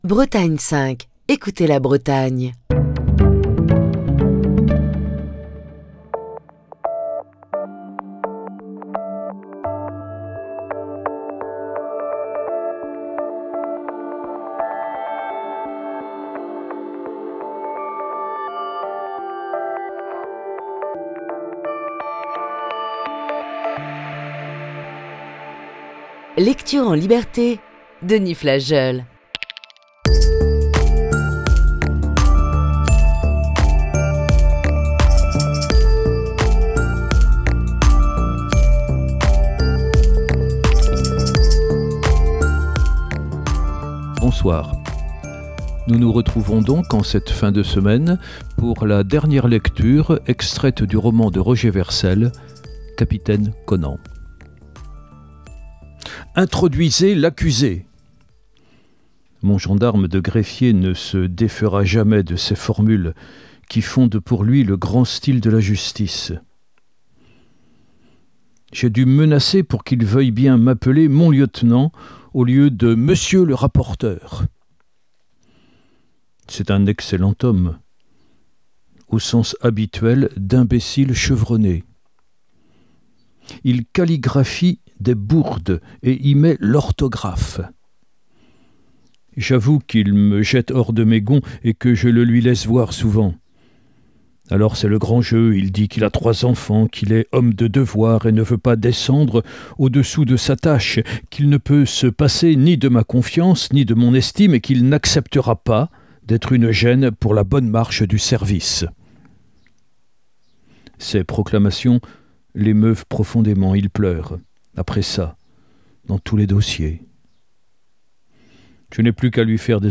lecture du roman